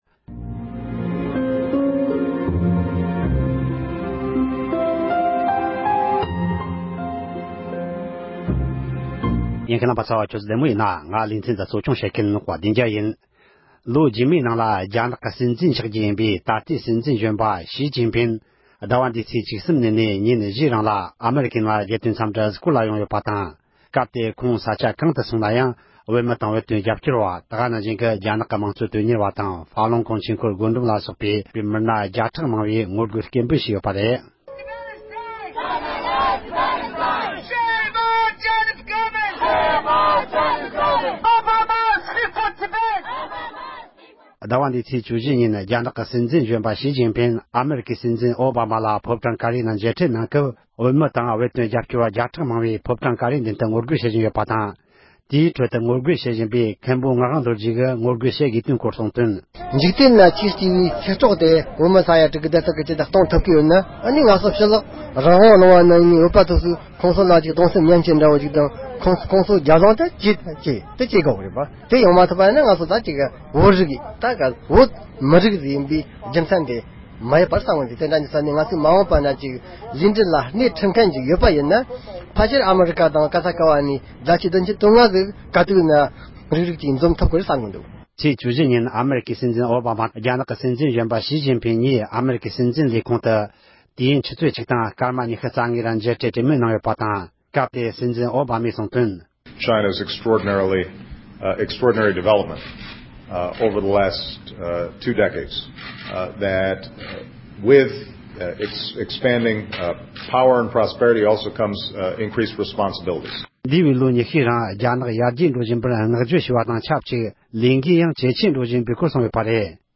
རྒྱ་ནག་གི་སྲིད་འཛིན་གཞོན་པ་ཞི་ཅིན་ཕིང་ ཨ་རིའི་ནང་རྒྱལ་དོན་གཟིགས་བསྐོར་དུ་ཕེབས་ཡོད་པའི་སྐོར་གླེང་མོལ།